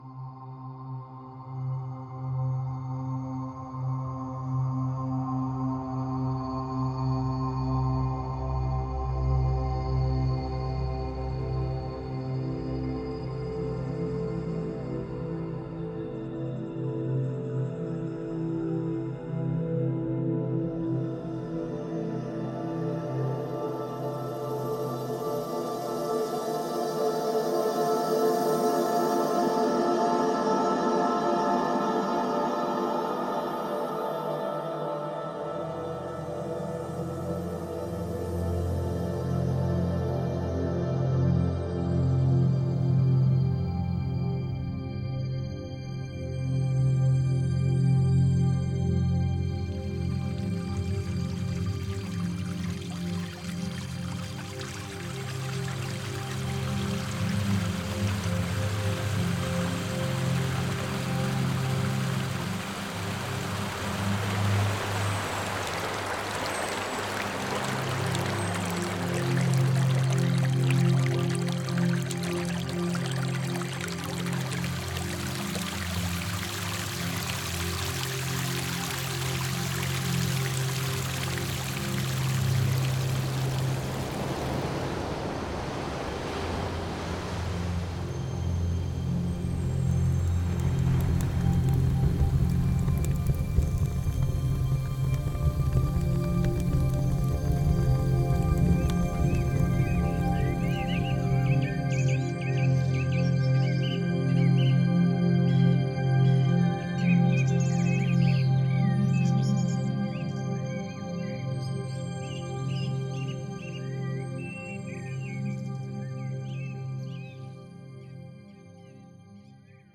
I picked two random shanti chimes from my collection and played them against each other. A minor and a major chords really produced quite a nice soothing Arabic-inspired tune!